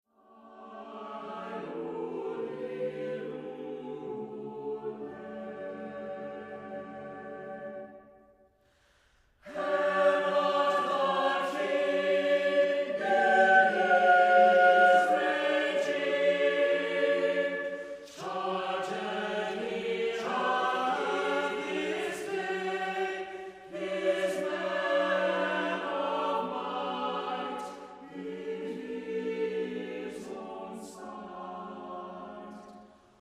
it's incredibly haunting.